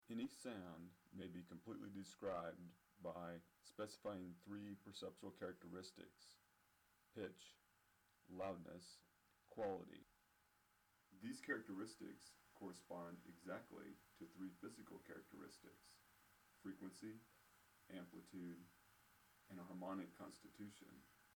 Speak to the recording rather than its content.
Here I use Audition to remove background noise, and convert a lossless format to a compressed mp3 file. AudioWithNoiseAndUms.mp3